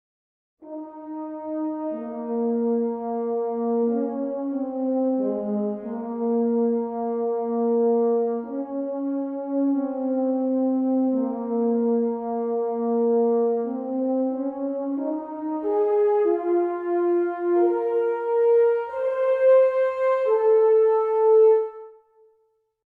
horn.ogg